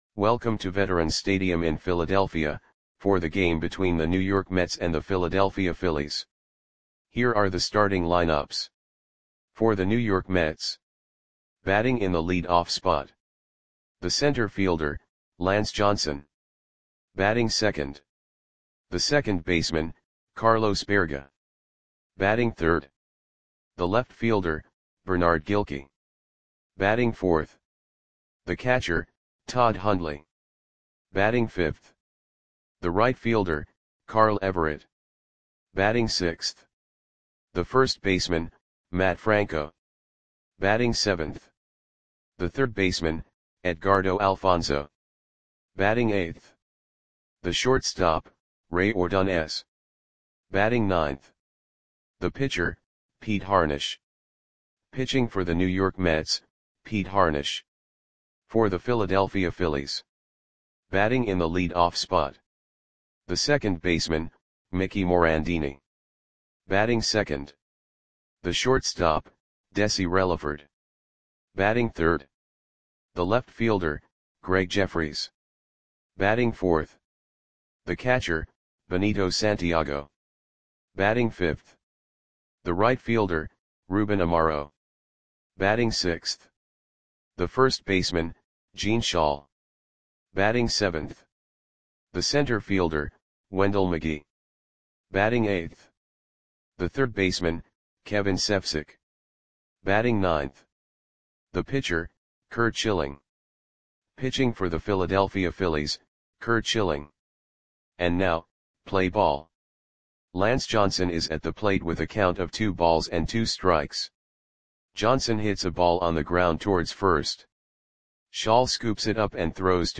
Audio Play-by-Play for Philadelphia Phillies on September 21, 1996
Click the button below to listen to the audio play-by-play.